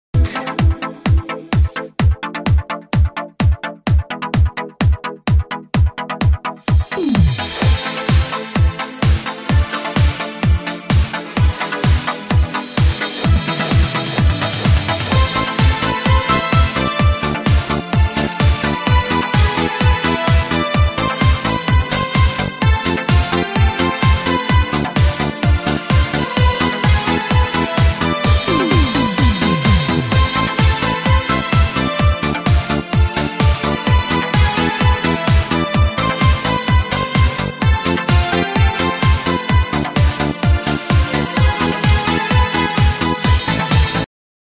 • Качество: 128, Stereo
без слов